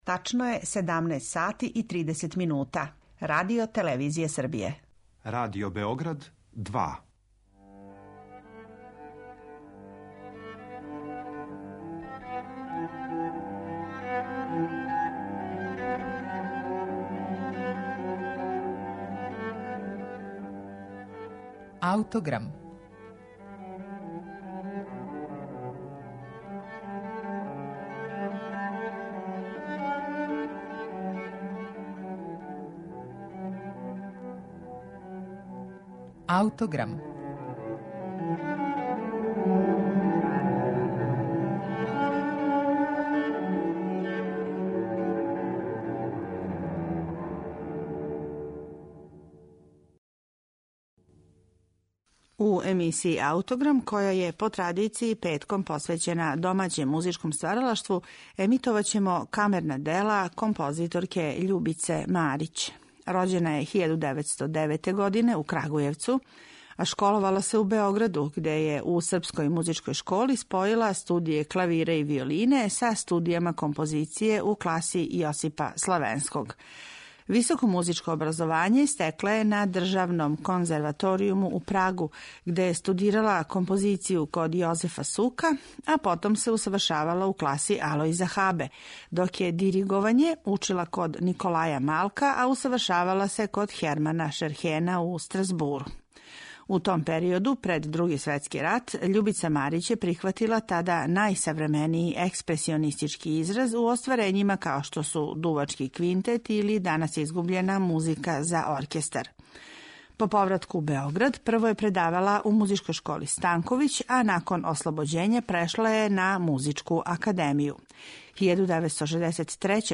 Eмисија 'Аутограм', по традицији петком посвећена домаћем музичком стваралаштву, представиће камерна дела Љубице Марић, једне од наших најзначајнијих композиторских личности прошлог века.
Емисију ће започети њено дело "Асимптота" за виолину и гудаче, настало 1986. године.